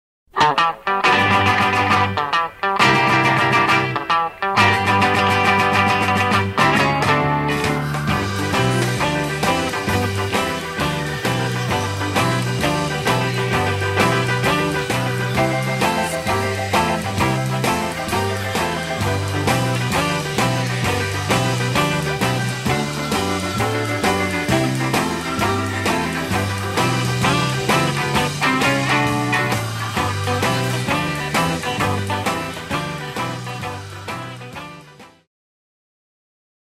They called this concept "Left Minus Right" (L-R).
OOPSed (Excerpt)